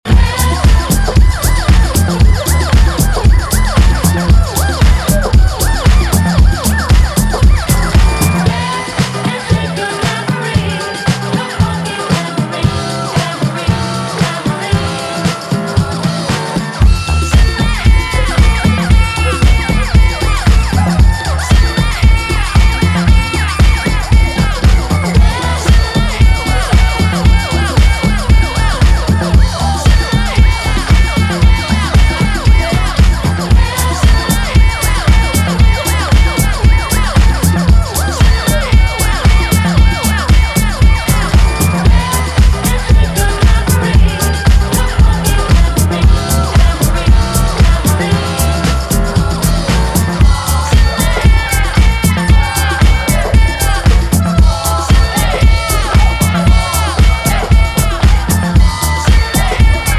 重厚なボトムにディスコサンプルが舞う
長いディスコ/ハウス史への眼差しを感じさせる楽曲を展開